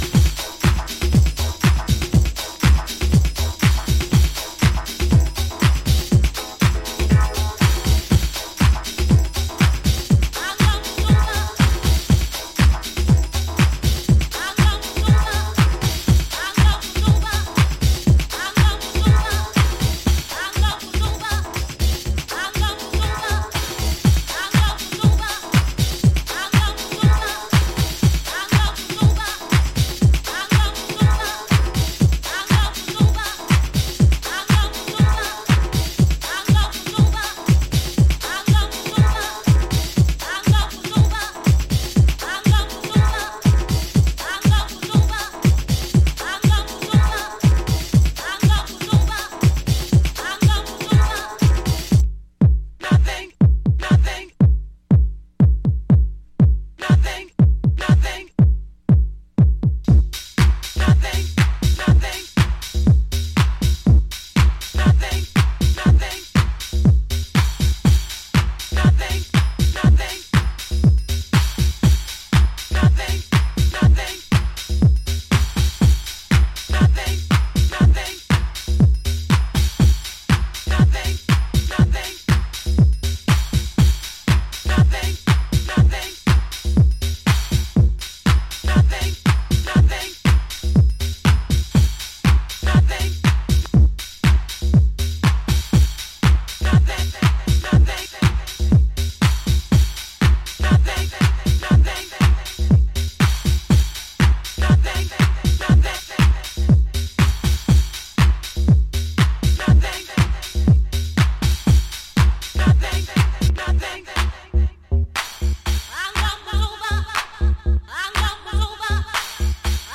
Driving, funky, stripped back